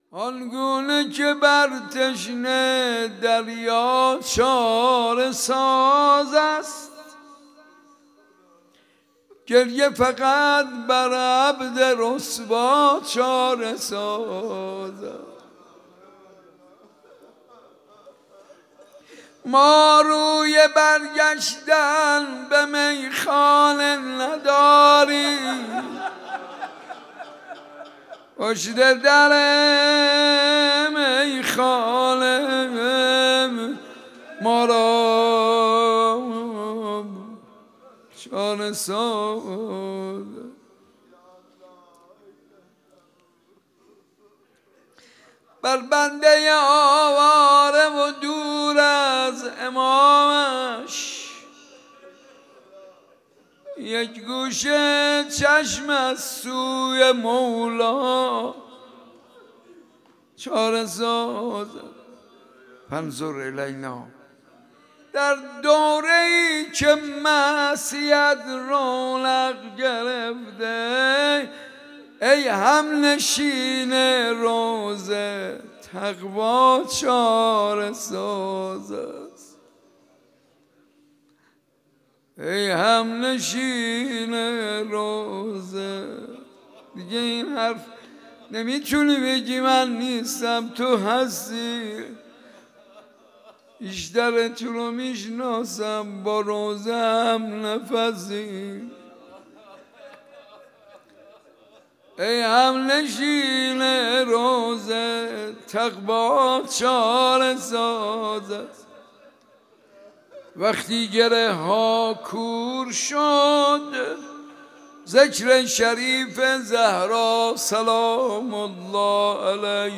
ماه مبارک رمضان ۱۴۰۳